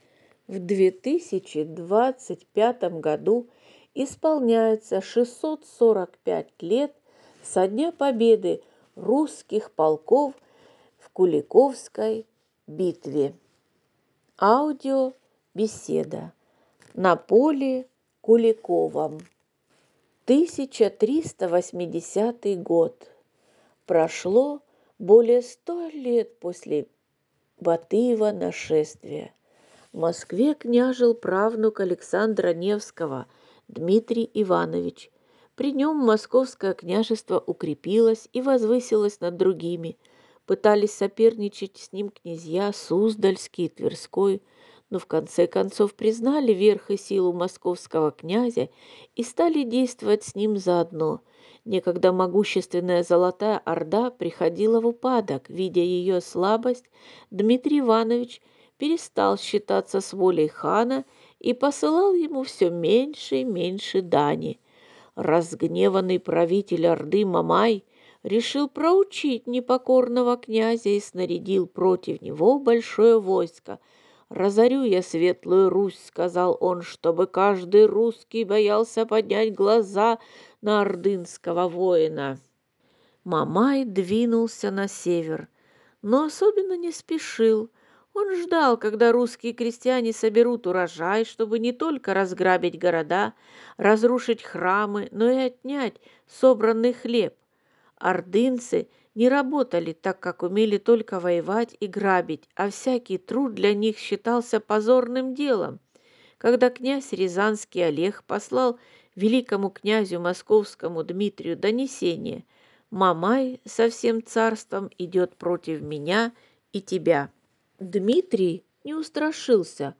В аудиобеседе прозвучали фрагменты из повести «Сказания о Мамаевом побоище» и стихотворение А. Блока «На поле Куликовом».